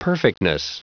Prononciation du mot perfectness en anglais (fichier audio)
Prononciation du mot : perfectness